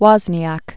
(wŏznē-ăk), Stephen Born 1950.